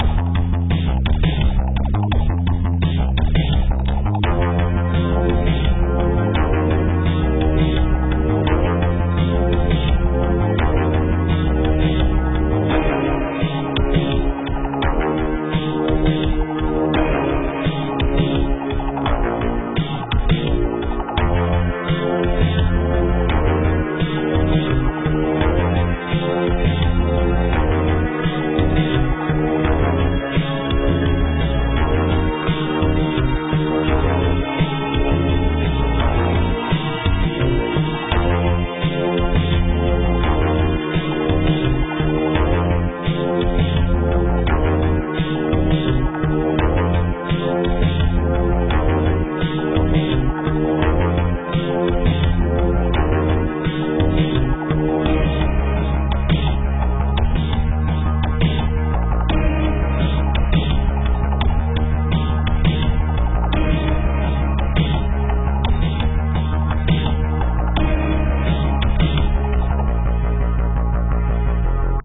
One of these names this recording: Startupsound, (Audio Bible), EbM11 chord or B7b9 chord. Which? Startupsound